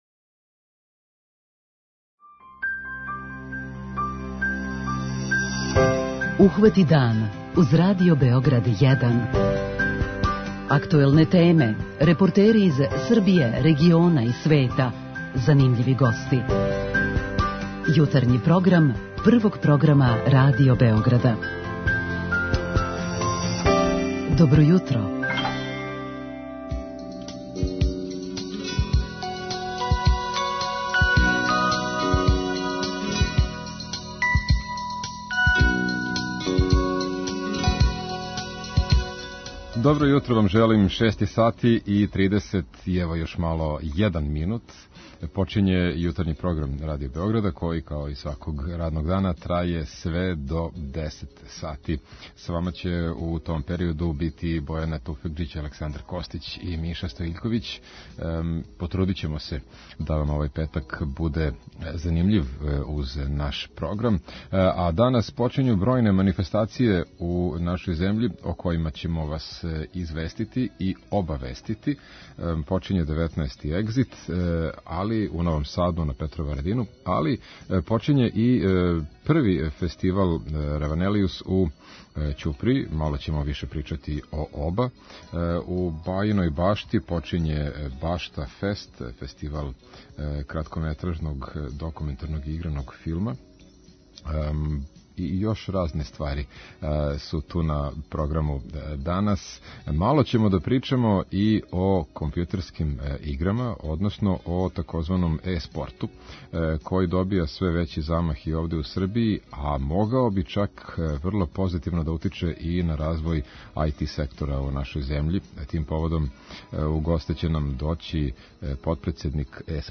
преузми : 37.77 MB Ухвати дан Autor: Група аутора Јутарњи програм Радио Београда 1!